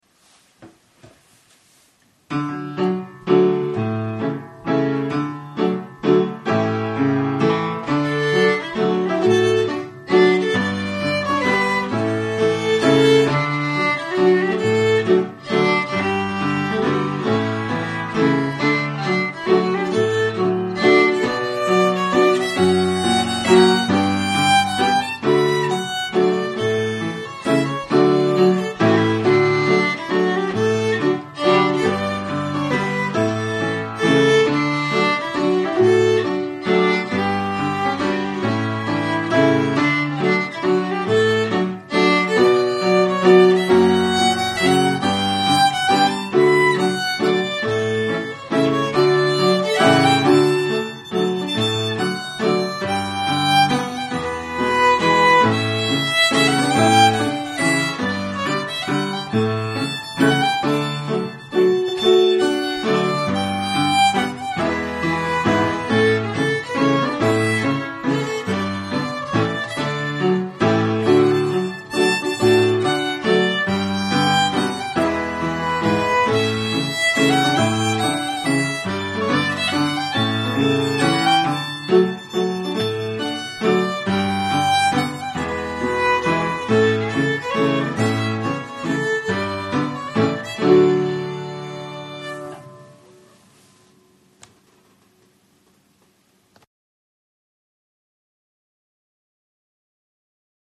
Waltz - D Major